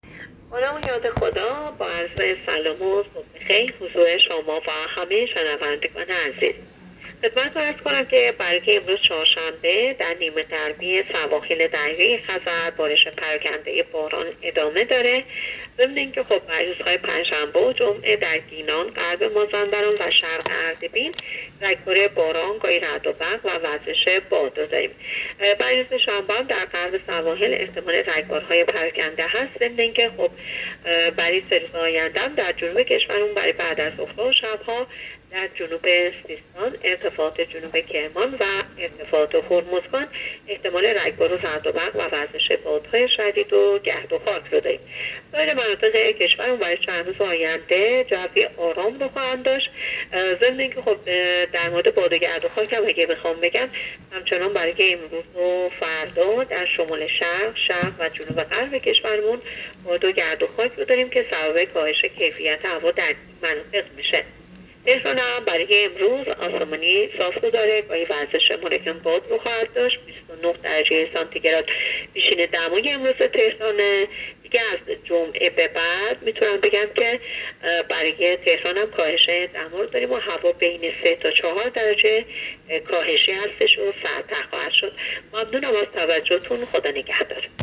گزارش رادیو اینترنتی پایگاه‌ خبری از آخرین وضعیت آب‌وهوای ۲۳ مهر؛